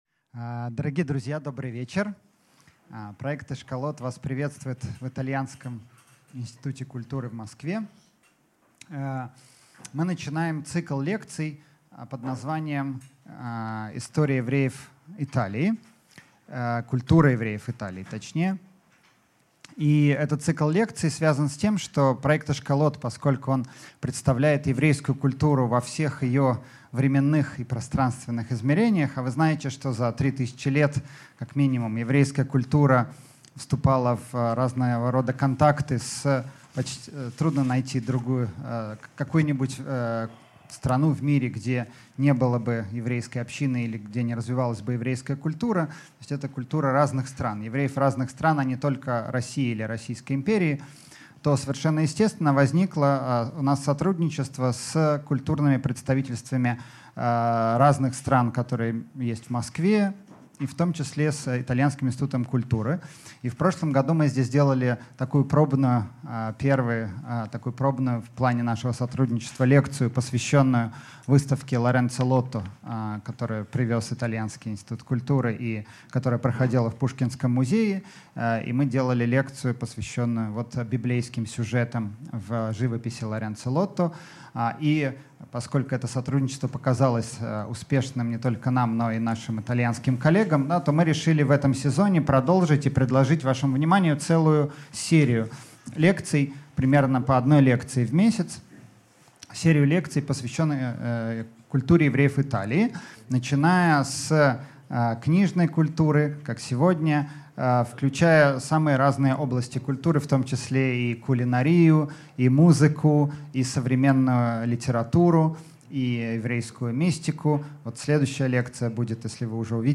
Аудиокнига Италия как колыбель еврейского книгопечатания | Библиотека аудиокниг